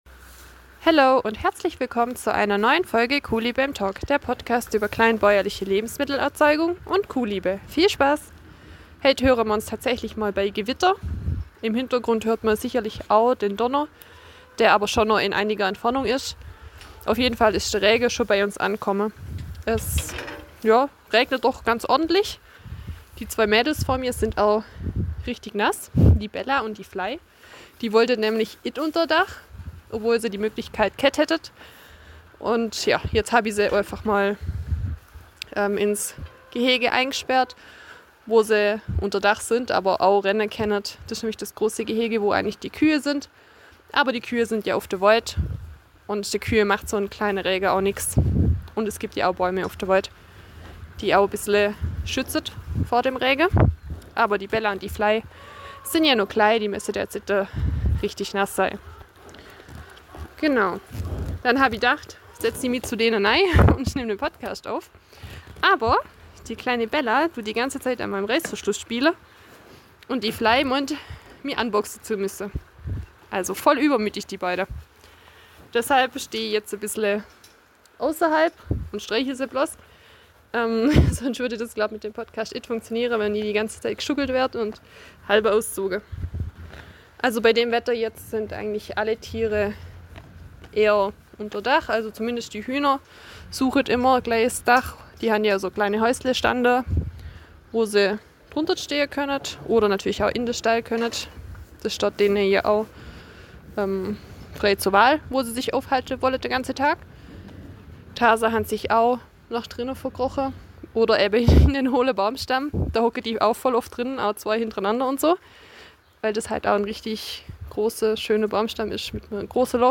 Die erste Folge bei Gewitter glaube ich.